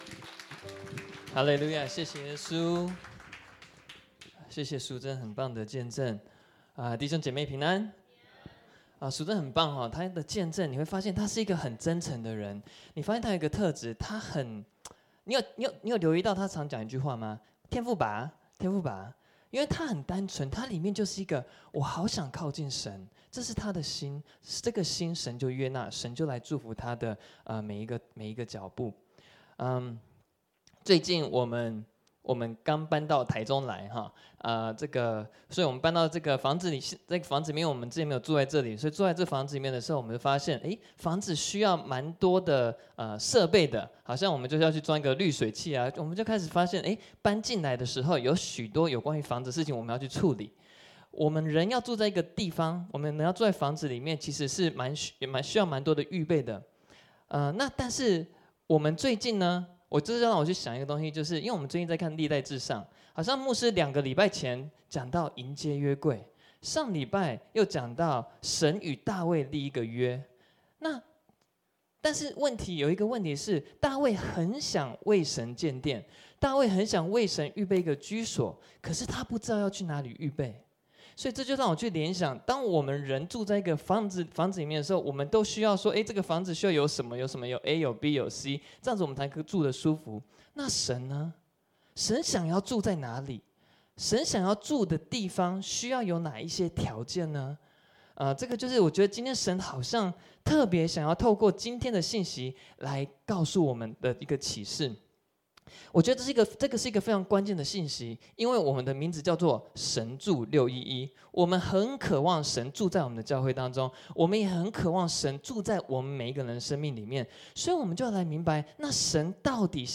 b_主日信息